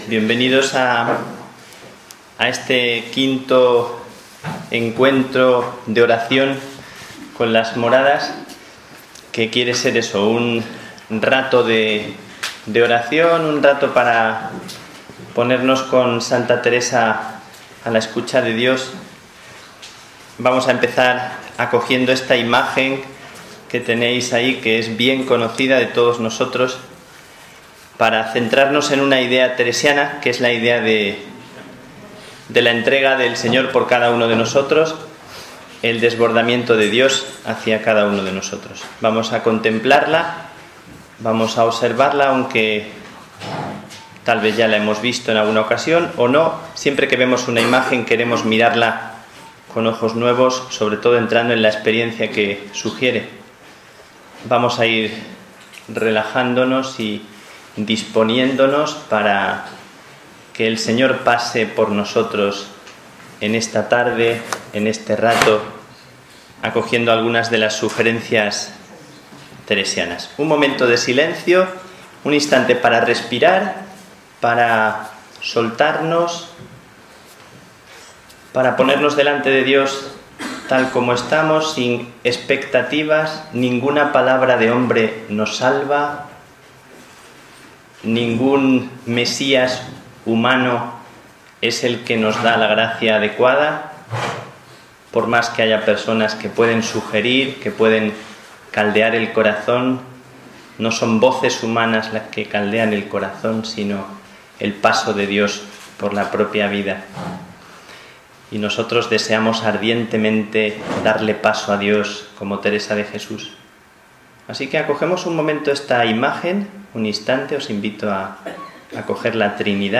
Aquí os paso la 5ª Sesión del Taller de Oración que tuvimos ayer.